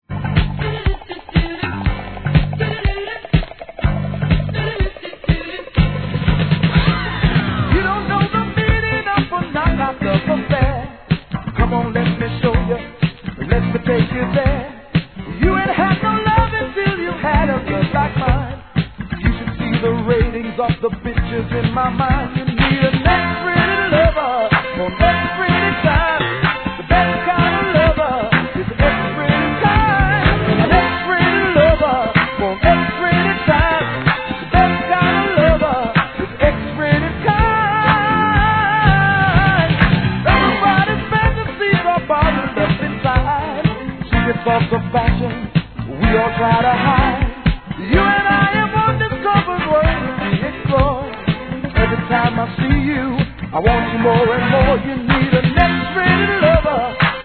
店舗 ただいま品切れ中です お気に入りに追加 1983年、JAMAICANバラード!